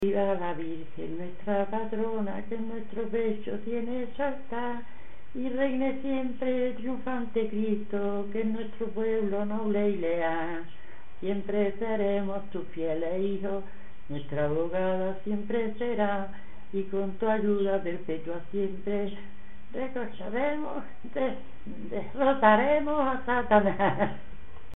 Canciones religiosas Icono con lupa
Alhama (Granada. Comarca) Icono con lupa
Agrón (Granada) Icono con lupa
Secciones - Biblioteca de Voces - Cultura oral